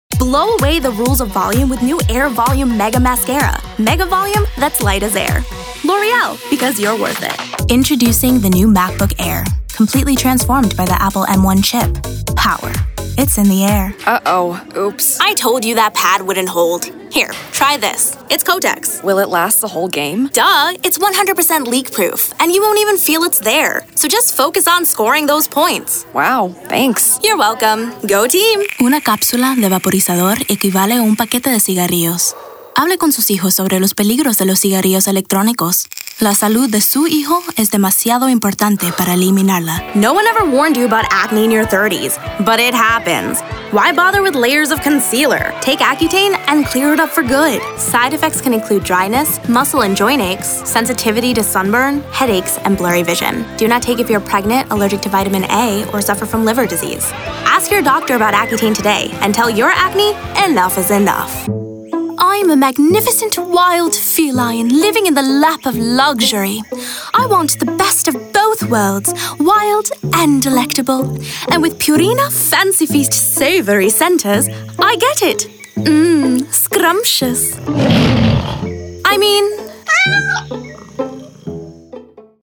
Voice-over
Demo Reel